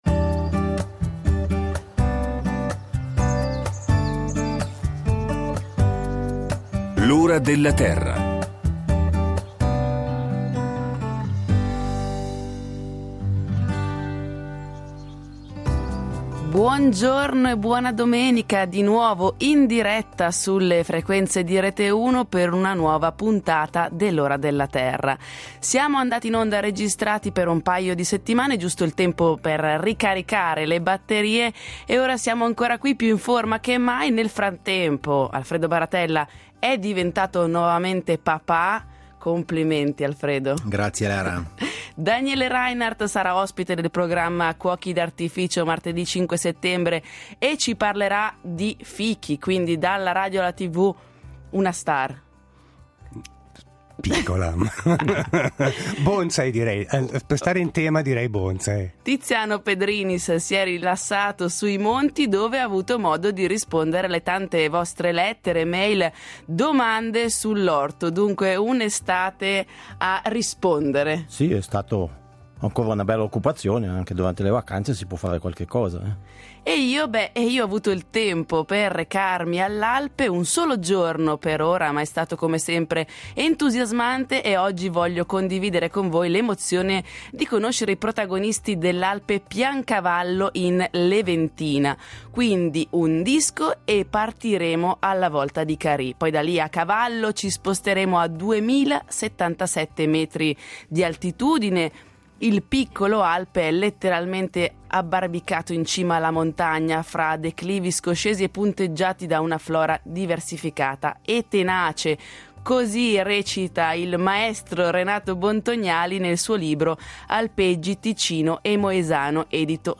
L’Ora della Terra vi propone questo piccolo reportage.